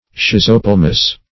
Search Result for " schizopelmous" : The Collaborative International Dictionary of English v.0.48: Schizopelmous \Schiz`o*pel"mous\, a. [Schizo- + Gr. pe`lma the sole of the foot.]
schizopelmous.mp3